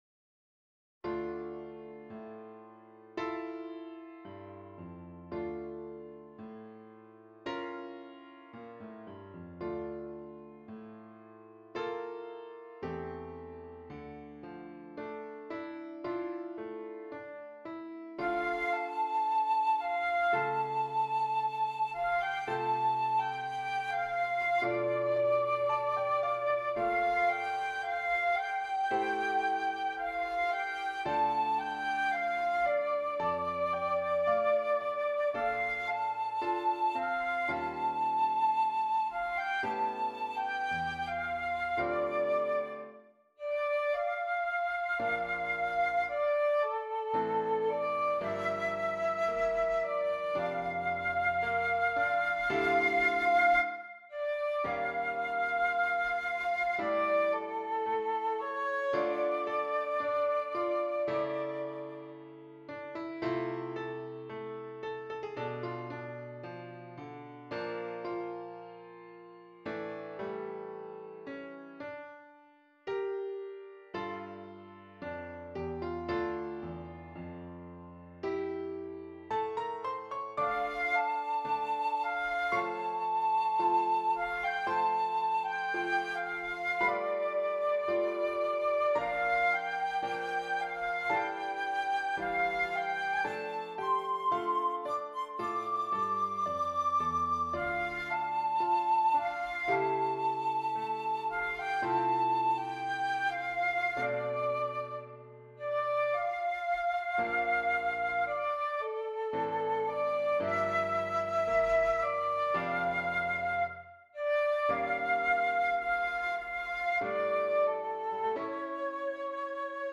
Flute and Keyboard